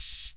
Index of /musicradar/essential-drumkit-samples/Vintage Drumbox Kit
Vintage Open Hat 04.wav